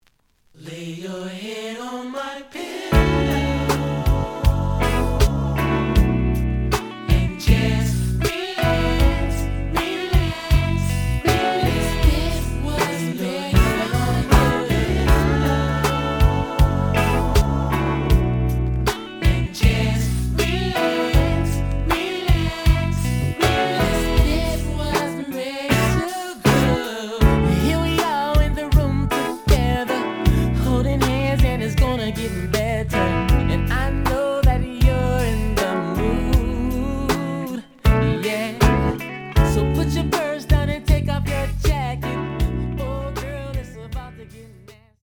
The audio sample is recorded from the actual item.
●Genre: Hip Hop / R&B
Slight edge warp.